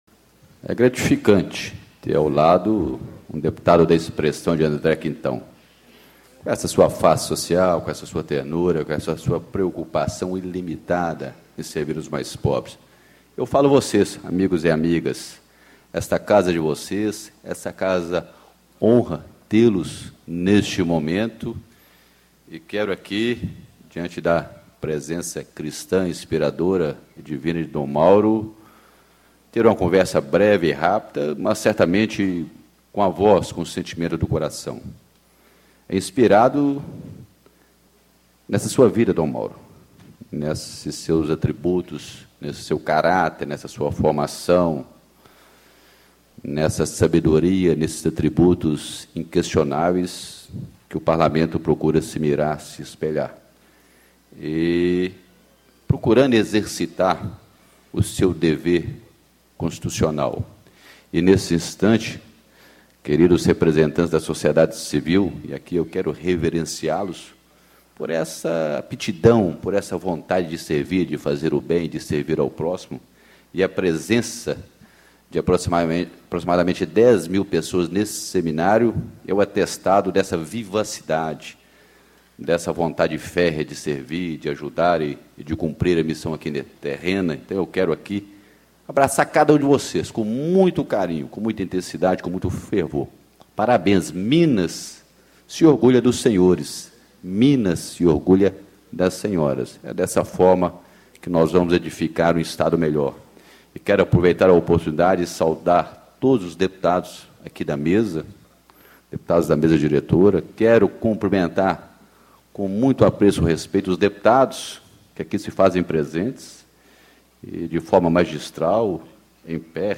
Deputado Dinis Pinheiro, PSDB, Presidente da Assembleia Legislativa do Estado de Minas Gerais - Solenidade de Promulgação da Emenda à Constituição 86/2011 que inclui a erradicação da pobreza entre os objetivos prioritários do Estado de Minas Gerais